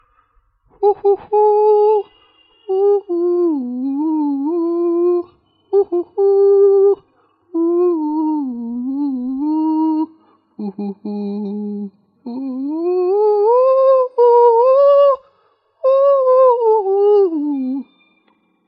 我哼了一段。
楼主寻找曲子亲自哼得调调下载